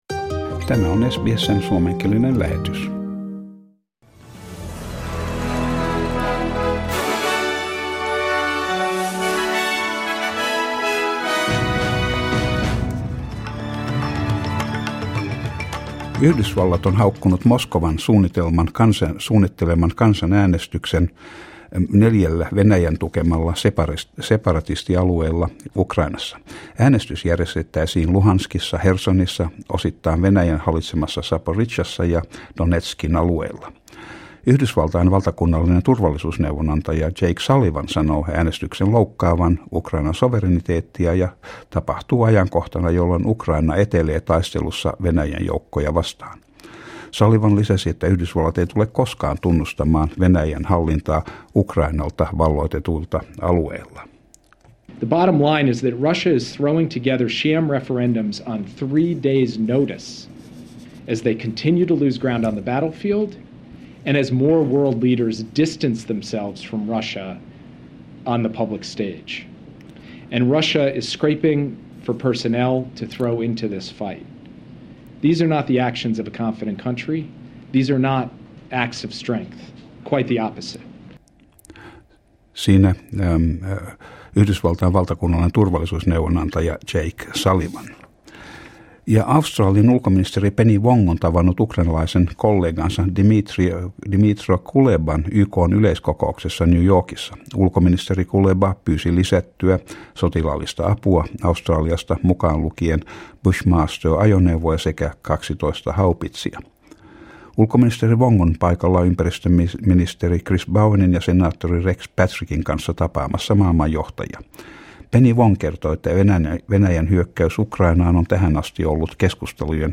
Suomenkieliset uutiset Source: SBS